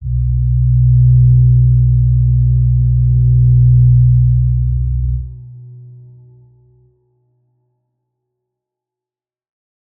G_Crystal-B2-pp.wav